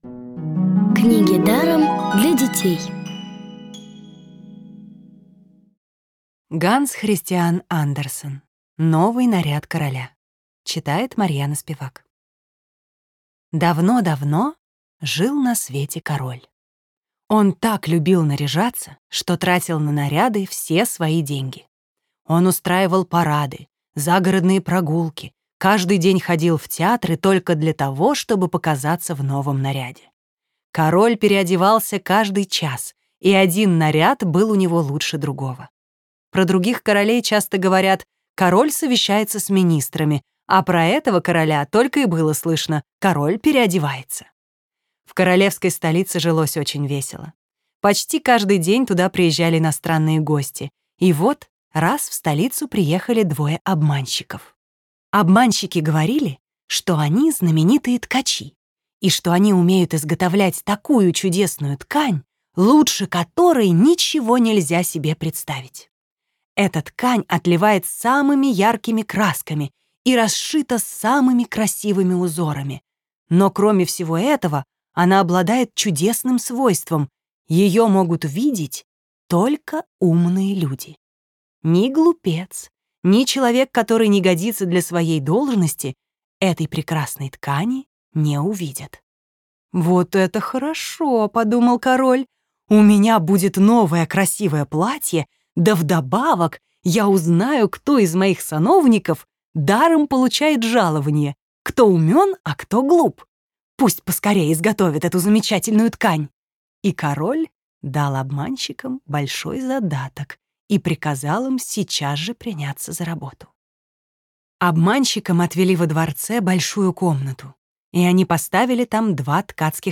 Аудиокнига бесплатно «Новый наряд короля» от Рексквер. Сказки Андерсена.
Аудиокниги онлайн – слушайте «Новый наряд короля» в профессиональной озвучке и с качественным звуком.